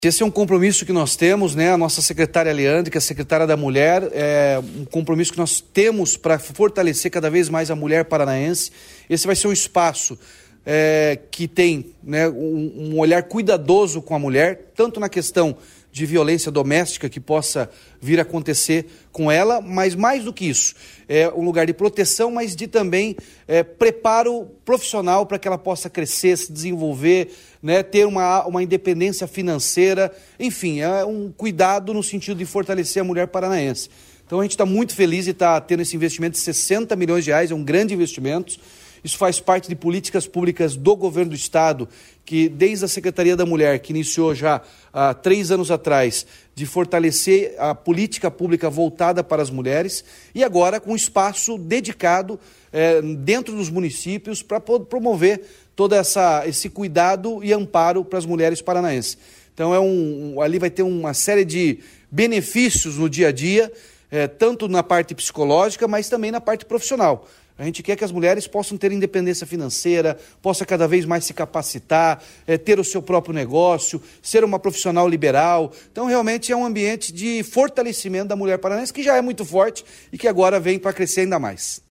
Sonora do governador Ratinho Junior sobre as 30 novas Casas da Mulher Paranaense